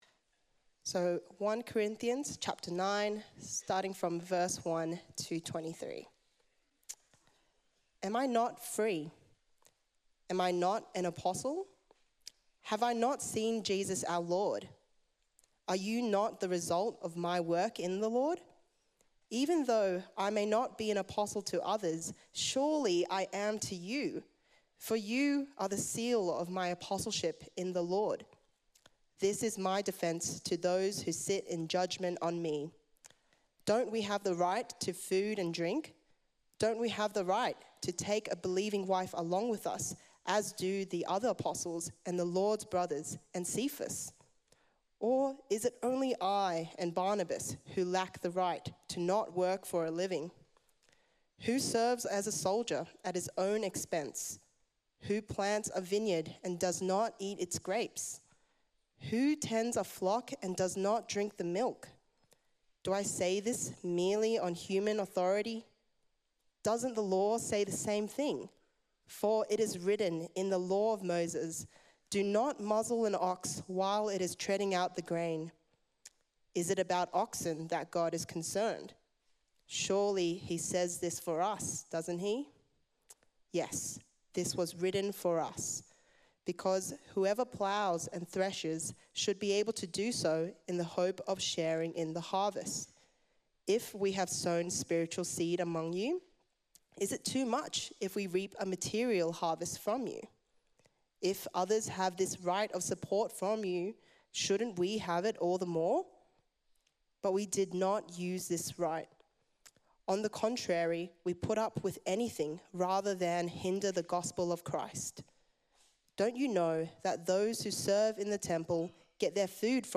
Kanishka Raffel, Anglican Archbishop of Sydney, gives the Lords Supper Address at CMS NSW & ACT Summer School 2025. All for the sake of the gospel (1 Corinthians 9:1-23)